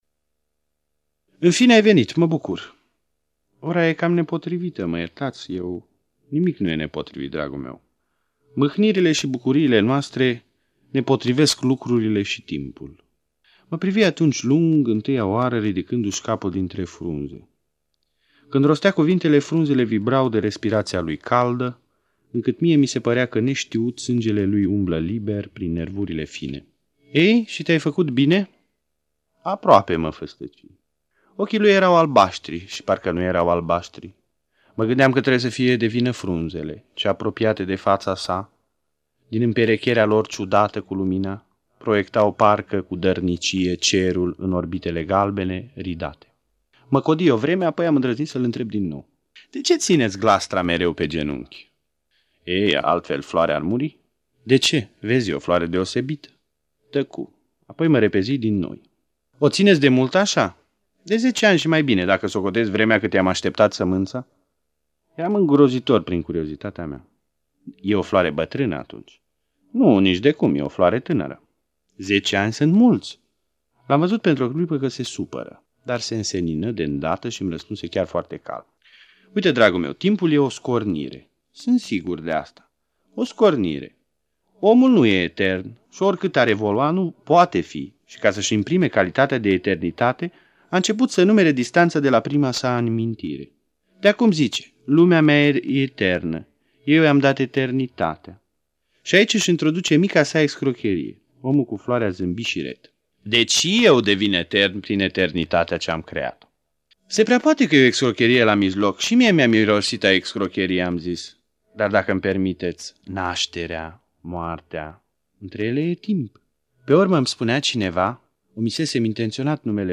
Memoria fonotecii de Aur ne restituie astăzi”vocea profundă şi calmă a unui bărbat de nici 44 de ani”-vocea scriitorului şi ctitorului de cultură Romulus Guga.